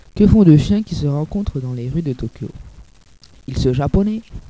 Nos blagues :